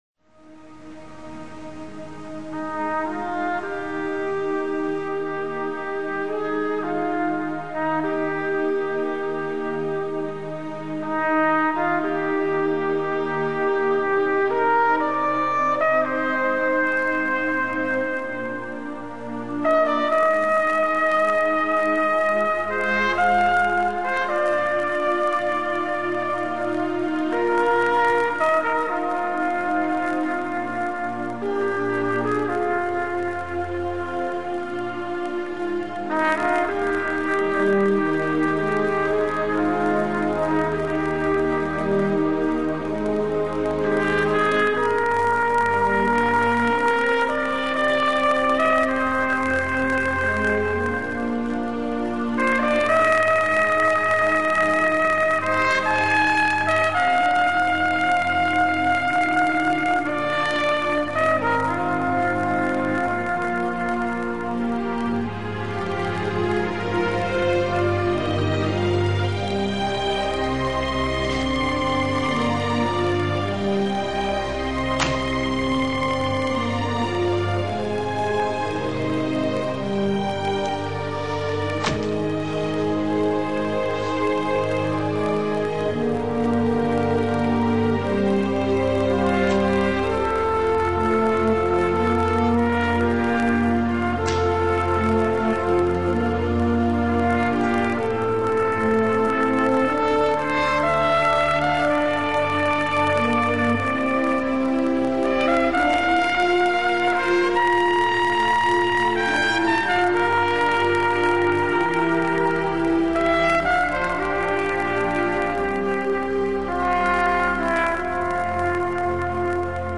Background music to the final moments of the first season